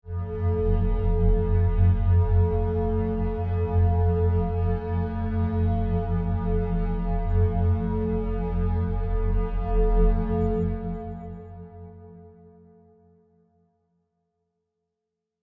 黑暗环境 " 环境声音 13
描述：可怕的环境声音......几乎是音乐，非常适合电影或游戏中的恐怖场景。
录音机是由7弦电吉他、Line 6 Pod x3以及大量的后期处理、采样和VST效果器组成。
声道立体声